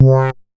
MoogVoice 010.WAV